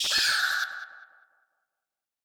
PixelPerfectionCE/assets/minecraft/sounds/mob/guardian/land_hit2.ogg at 937abec7bb071e95d485eece1172b7fc80203bcf
land_hit2.ogg